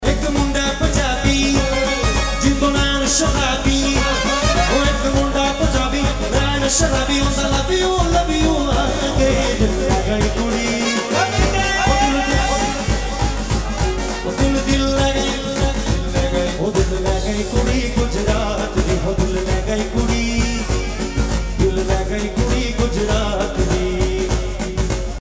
Dandiya Mix Ringtones